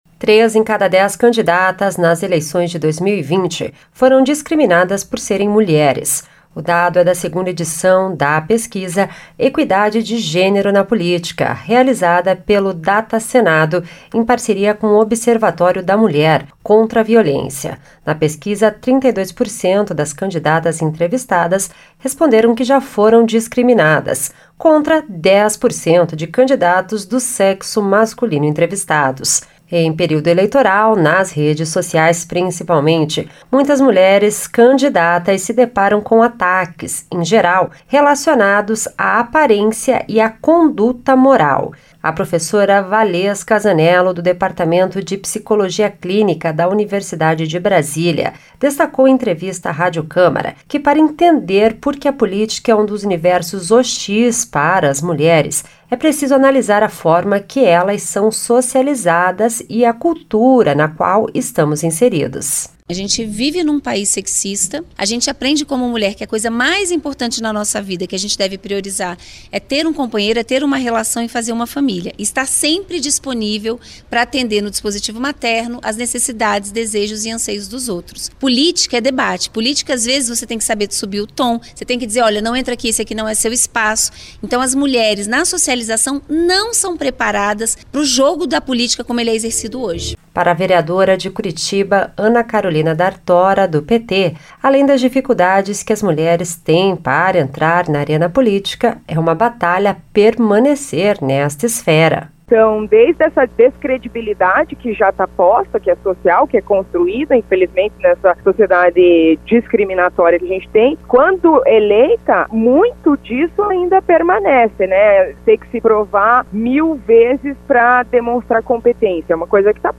Ela fala sobre algumas das violências que já sofreu.